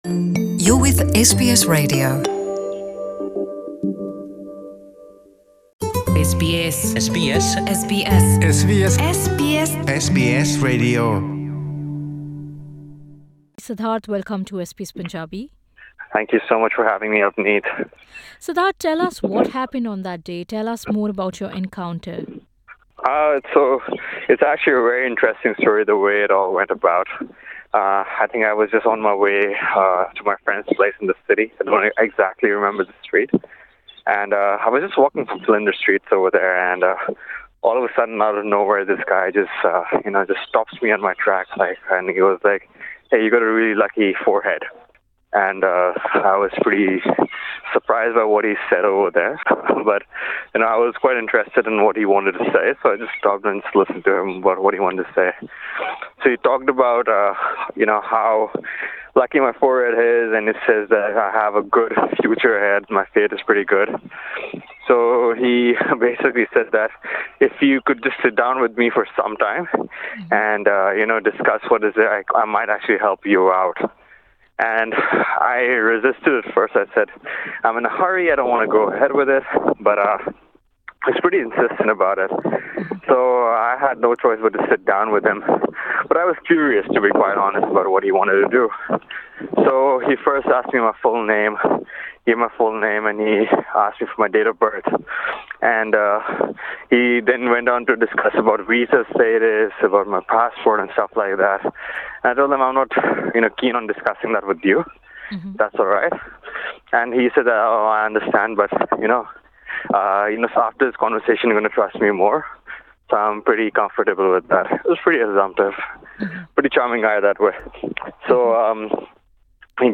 To listen to the audio interview with one of the victims